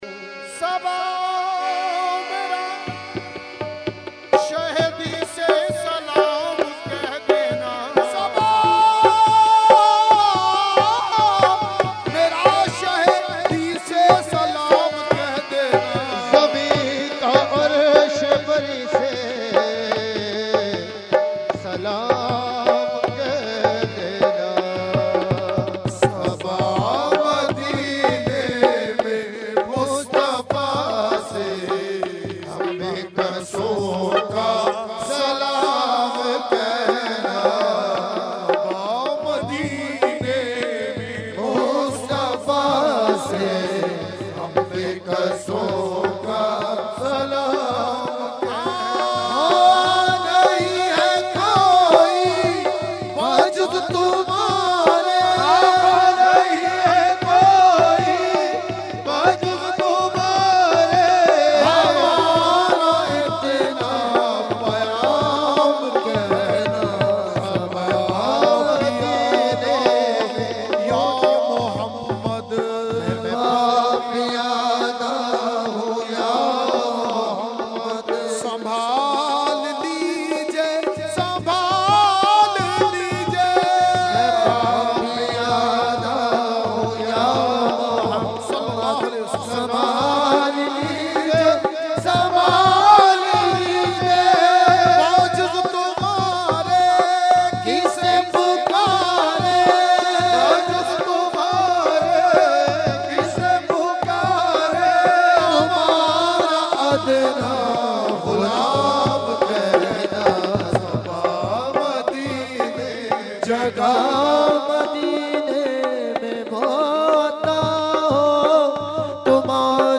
Category : Qawali | Language : UrduEvent : Urs Qutbe Rabbani 2020